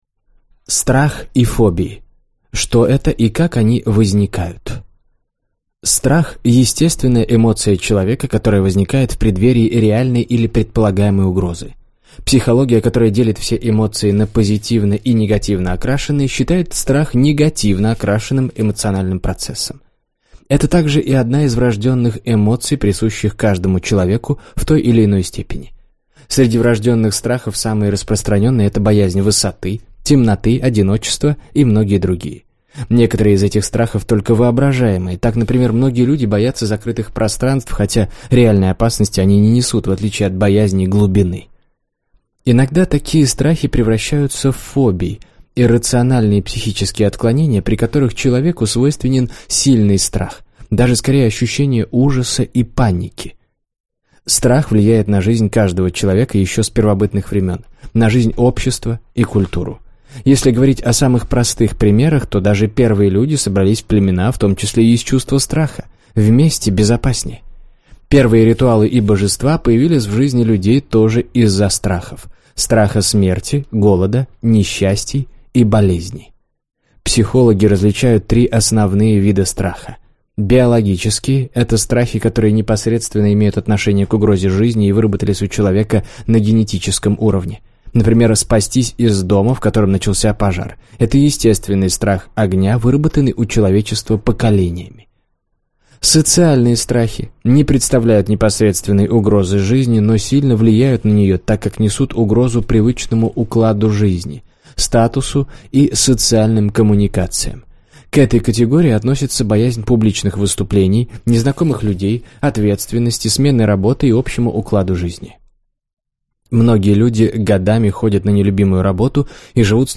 Аудиокнига Как победить страх. Секретные методики спецслужб | Библиотека аудиокниг